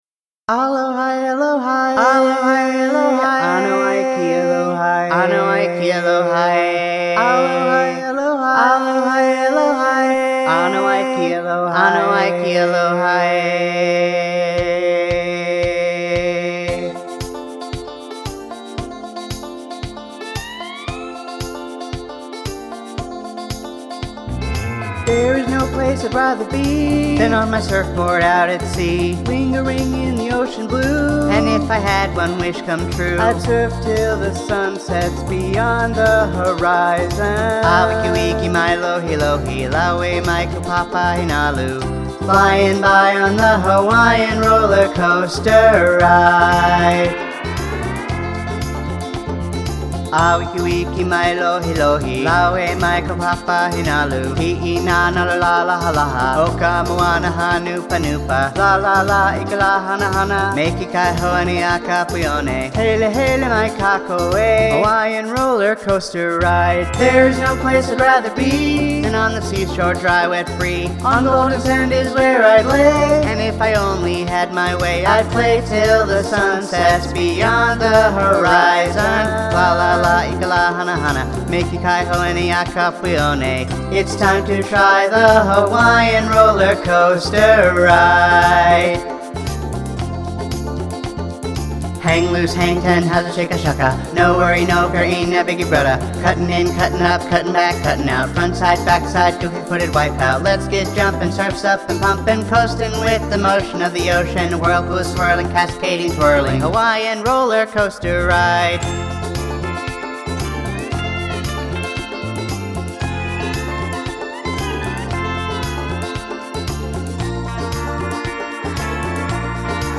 A song sung